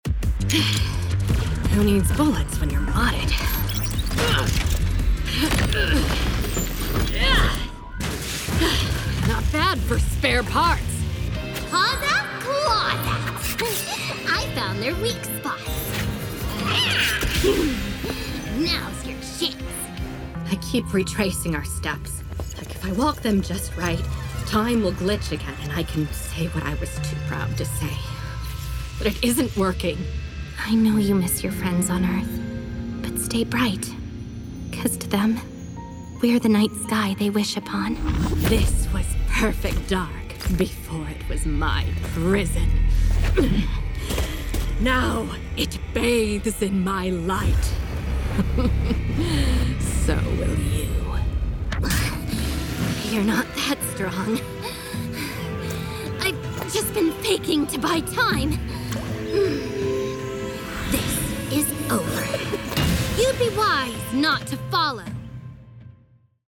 welcome ★ Kira Buckland is a voice actress based in the Los Angeles area.
She continues to be hired by a variety of repeat clients around the world for her versatility in voices and styles, and frequently appears as a featured guest at anime and comic conventions.
KiraBuckland-GameDemo.mp3